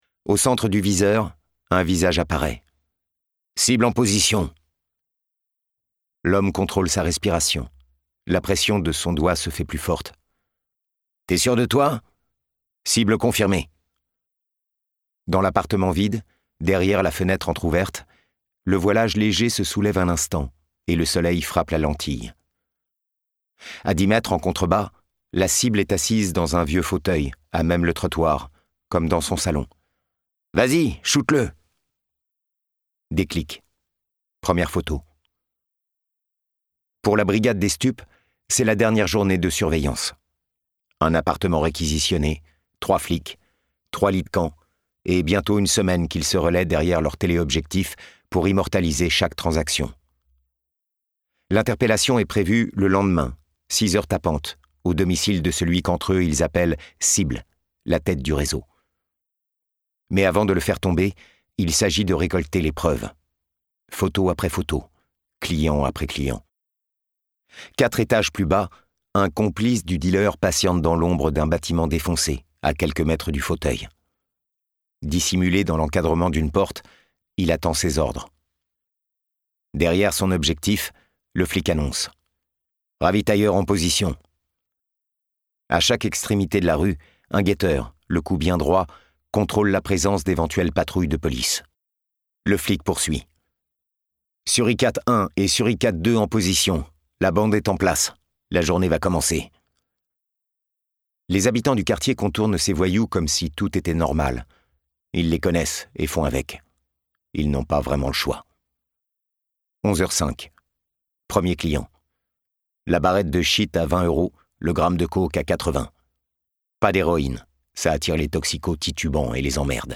la lecture coup de poing